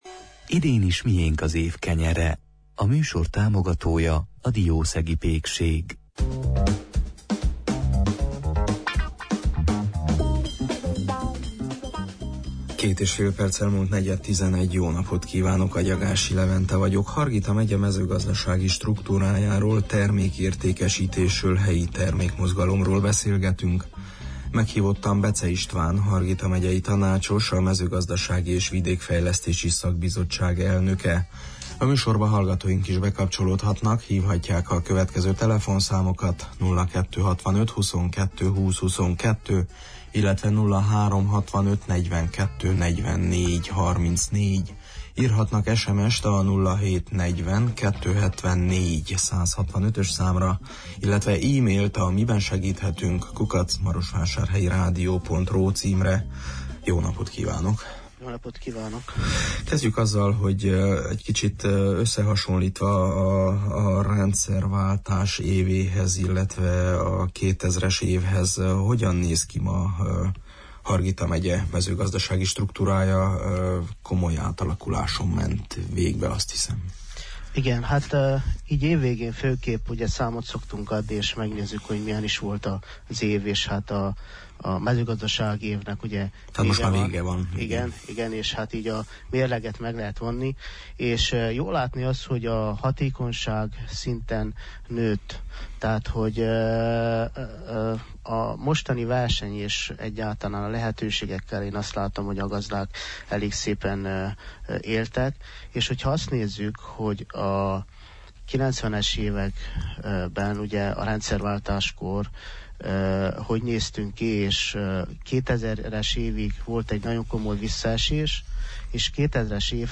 Hargita megye 400 ezer hektáros területének 80% -a kaszáló és legelő, a gazdaságok nagyrésze kisméretű, a szántóterületek felén is az állatállomány részére termelnek élelmet (silókukoricát, lucernát, takarmánygabonát), a gabonatermelésből származó szalmát felhasználják állatenyésztésben vagy eladják biomasszának – így jellemezte csütörtöki műsorunkban a megye mezőgazdaságát Becze István megyei tanácsos, a mezőgazdasági és vidékfejlesztési bizottság elnöke.